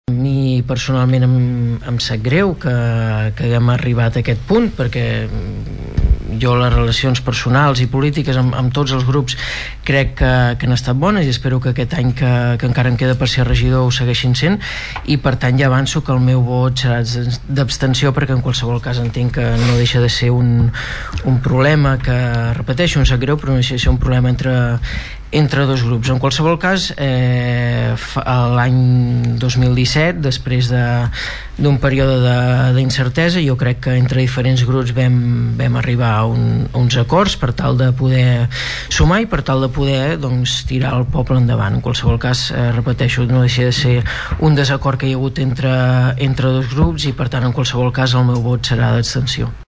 El ple de l’Ajuntament va debatre ahir una moció presentada pel grup municipal de Som Tordera-Entesa en defensa dels drets polítics i civils de les persones, la democràcia i la llibertat d’expressió i en contra de les conductes que vulnerin aquests principis fonamentals.
El regidor del PP, Xavier Martin es va abstenir i va reiterar que aquesta situació no deixa de ser un problema entre dos partits polítics concrets.